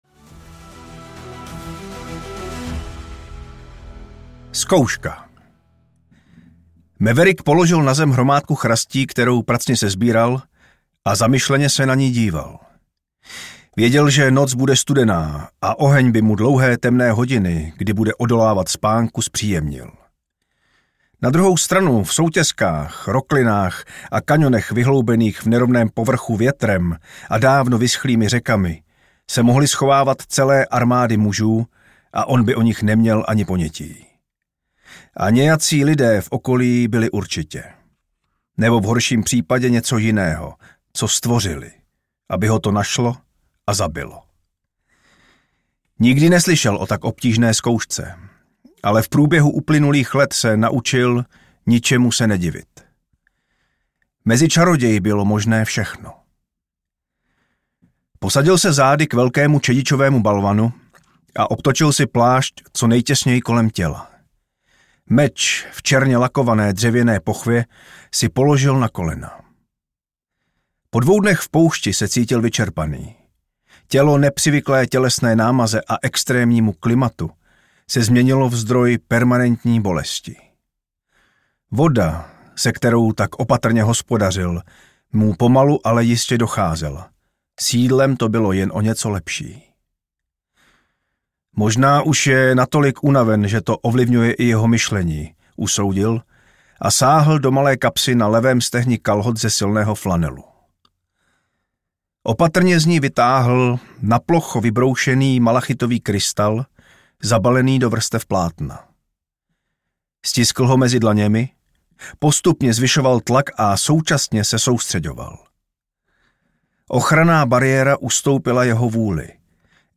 Ukázka z knihy
maverick-pesec-na-odpis-audiokniha